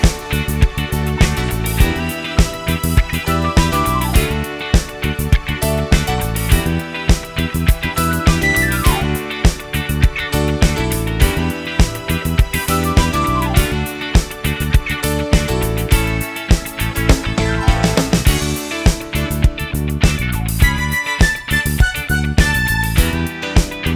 No Guitars Pop (1970s) 3:11 Buy £1.50